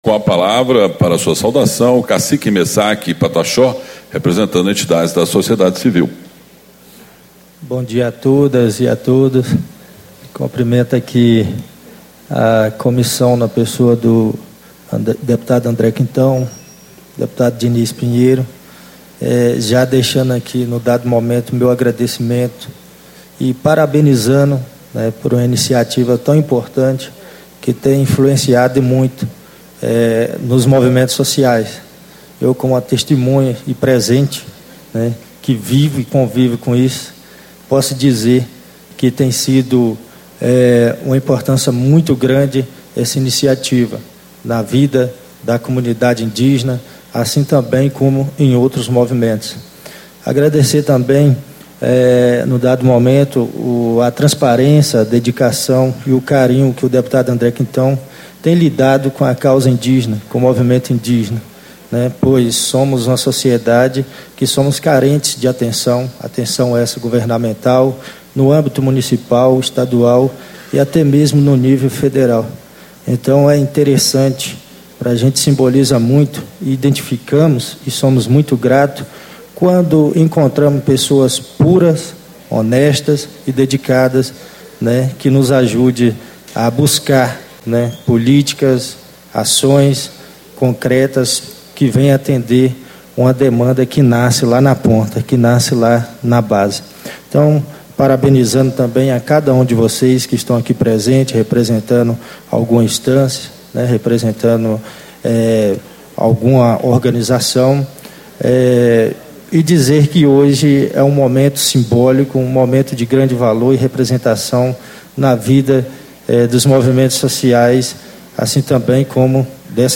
Discursos e Palestras